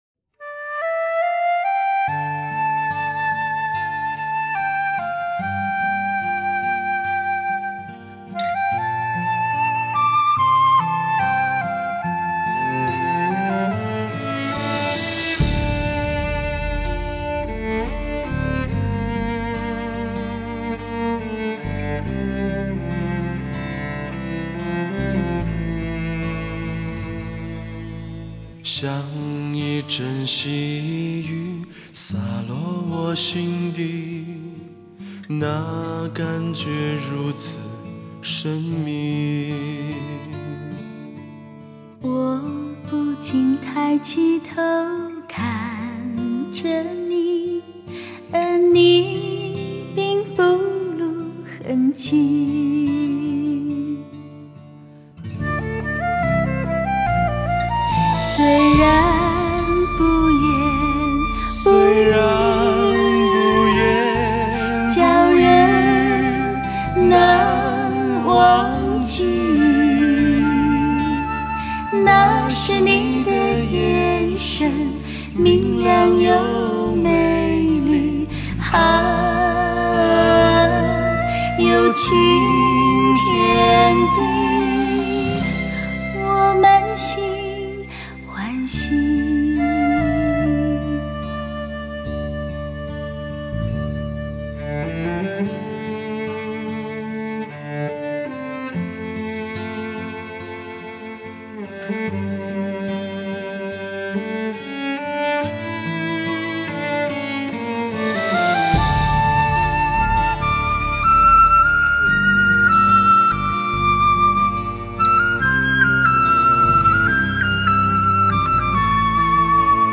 人声演绎自然，录音精致，醉人的情怀令人感动。
编曲中带点爵士味道，利用轻巧的女声伴音，是一番新滋味。